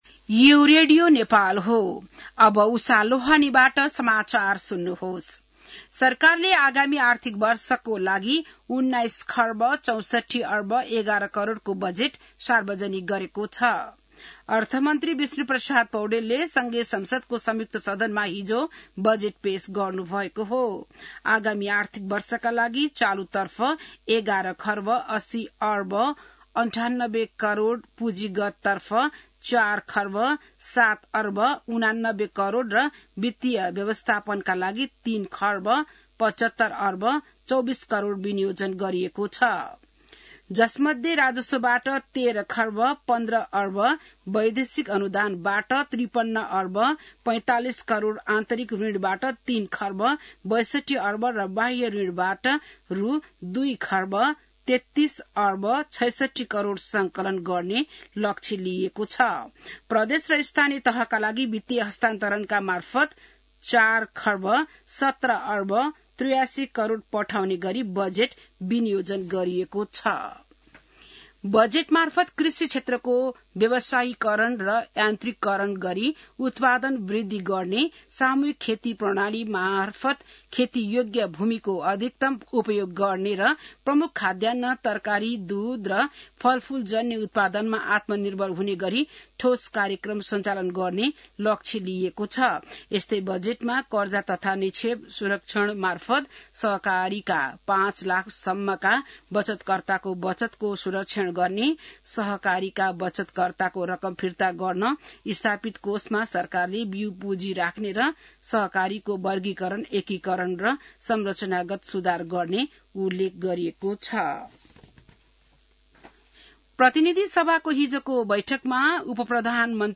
बिहान १० बजेको नेपाली समाचार : १६ जेठ , २०८२